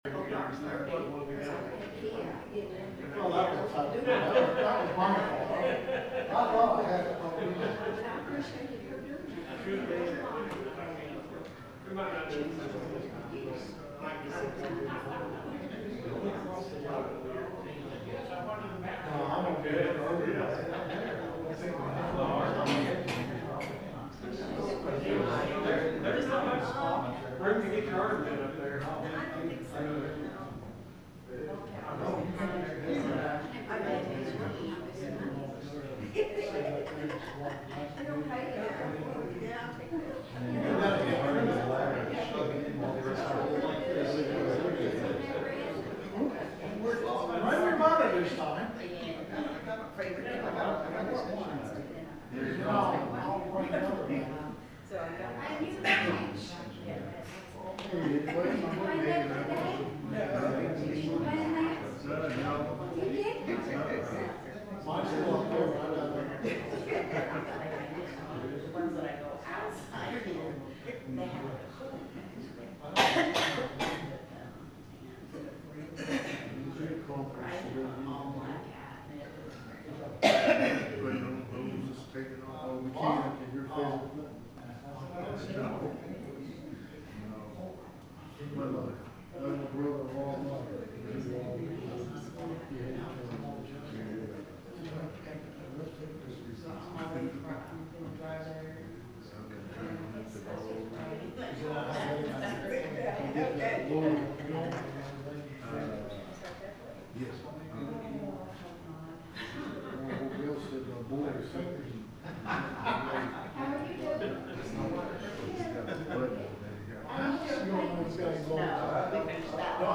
The sermon is from our live stream on 10/15/2025